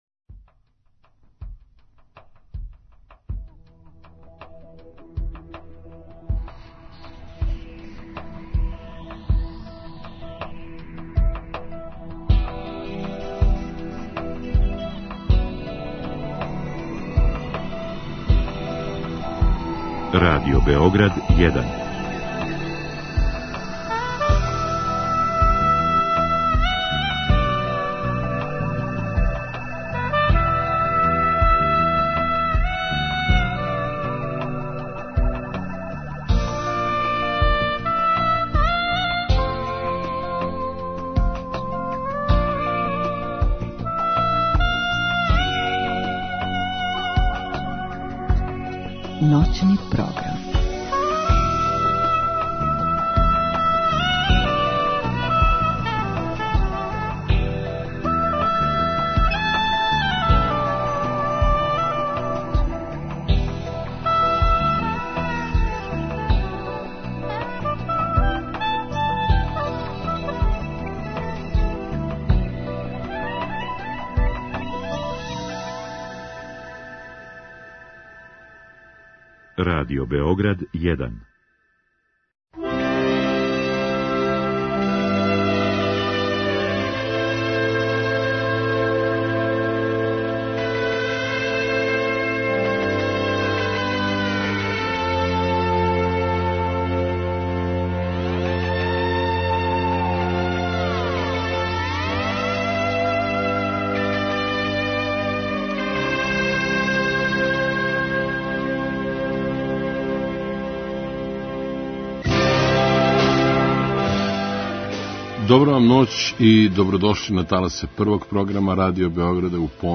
У ноћном програму Радио Београда 1 емисија Шимике и шампите доноси вам причу о Лују Армстронгу, подсећање на глумца Слободана Цицу Перовића, рецепт оригиналне проје, низ незаборавних хитова и још по нешто. У ноћи суботе на недељу - још једно путовање кроз 50. и 60. године.